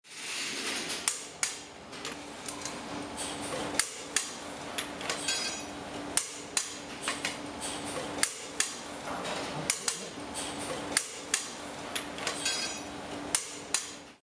第11回都営バス「特大トルクレンチ作業音」
機械のモーター音やアナウンス、発車の合図......。地下鉄、都電荒川線、都営バスのそれぞれの場所でしか聞くことができない音を収録しました。
自動車工場で行われる整備作業の音。全長1メートルほどの巨大な締め付け検査作業機器で、タイヤのホイールナットを締め直します。締め付けが一定の強さに達すると、カチンと音が鳴り、締め付けを確認できます。